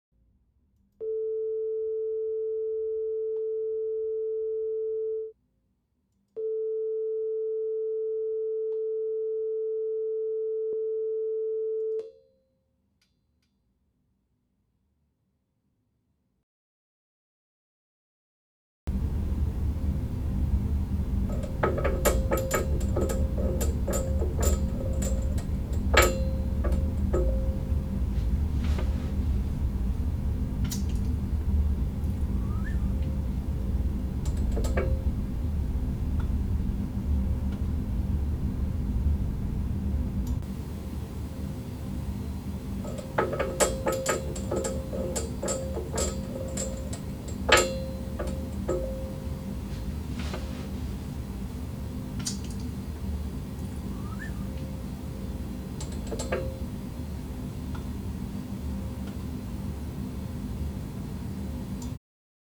While I had everything set up, I went ahead an disengaged the pad on the AKG, reset the input levels at 440Hz to be equal, and did the same noise test of rattling the two slides on the desk.
First are the two 440Hz tones, which were within 0.3dB. Next are the room sound and rattles boosted 35dB.
It does appear the P-420 has more self noise than the e935.